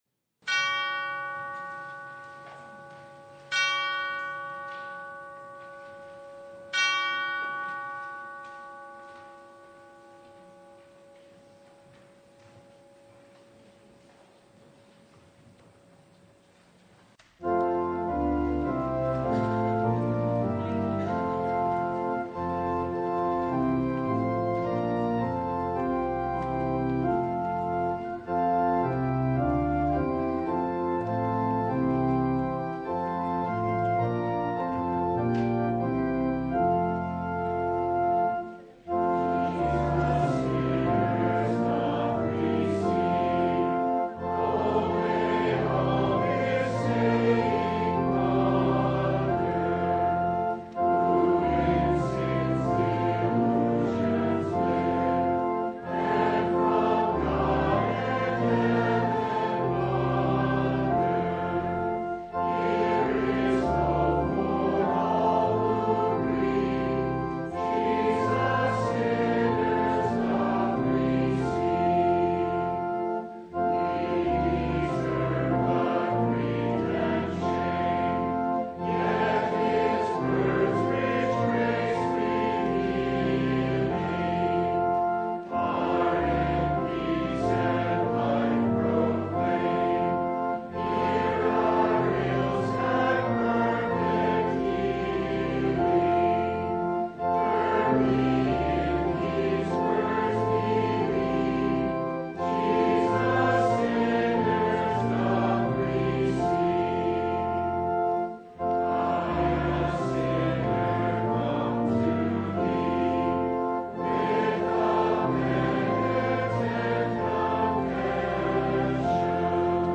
Luke 13:1-9 Service Type: Sunday Why?